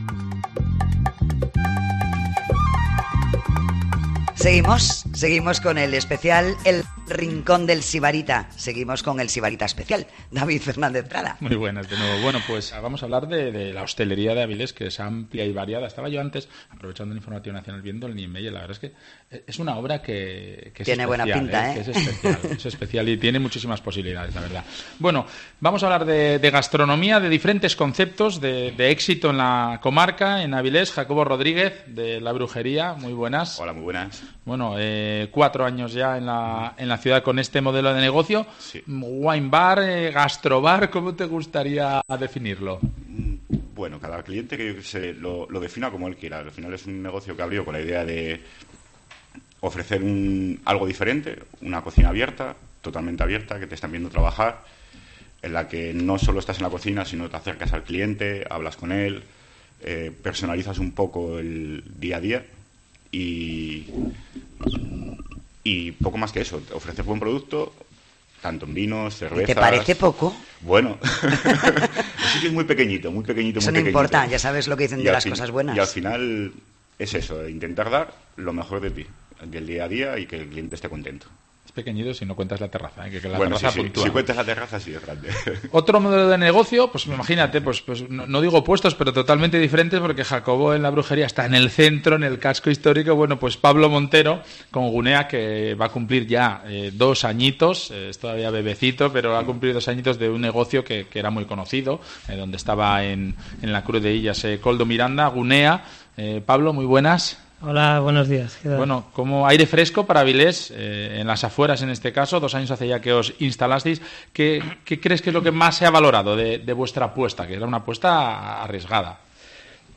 El Rincón del Sibarita se va de turismo, el programa se hace hoy desde la Oficina de Turismo de Avilés donde se van a descubrir las joyas gastronómicas de la ciudad.